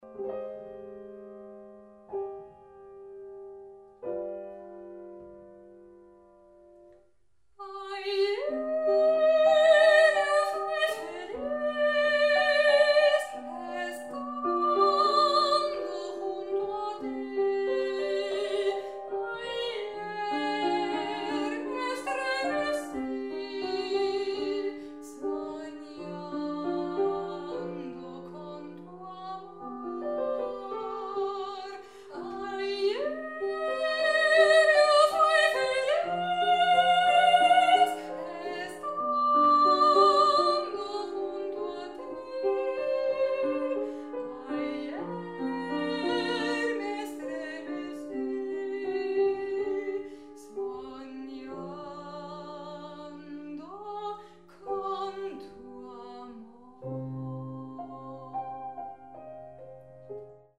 Sopran
Lieder